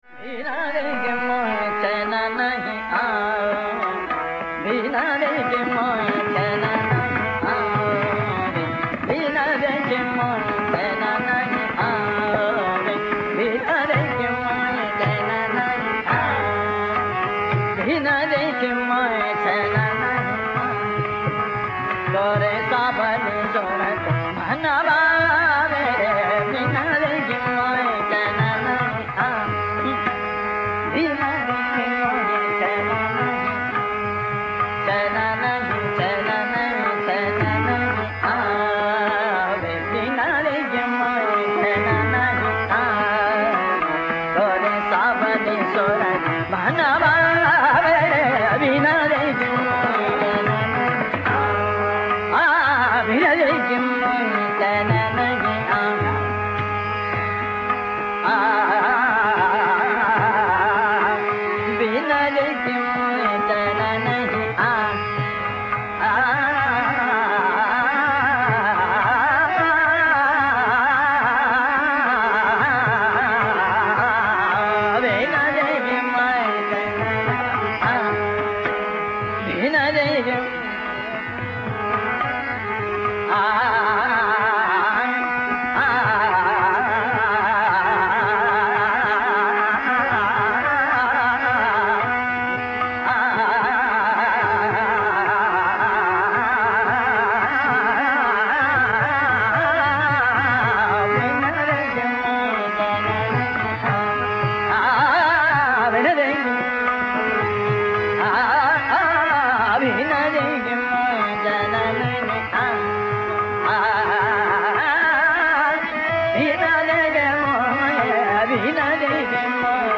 Raga Bilawal or Alhaiyya Bilawal